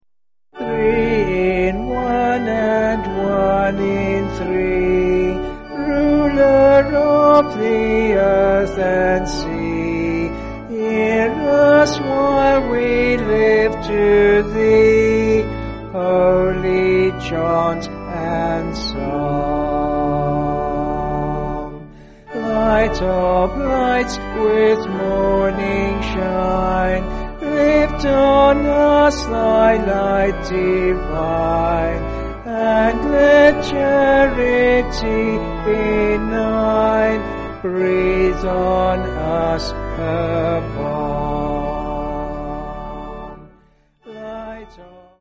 Vocals and Organ